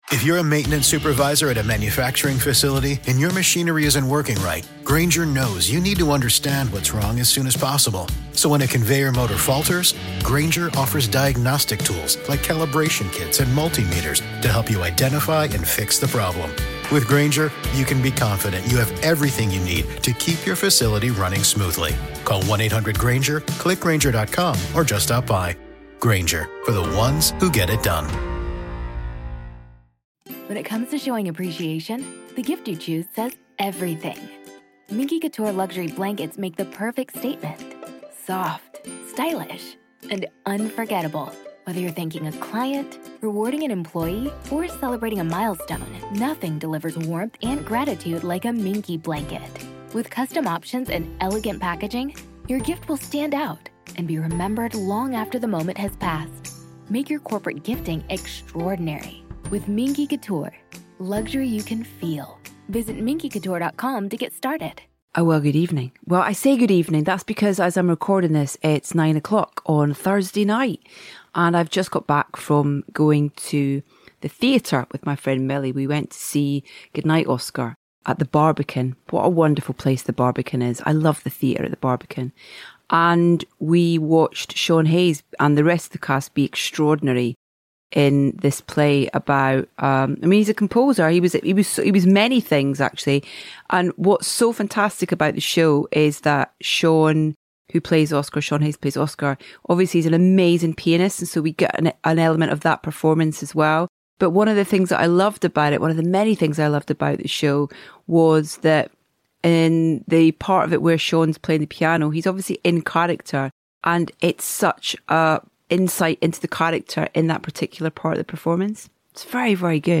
Appropriately, Joe joined us walking through the streets of Brooklyn!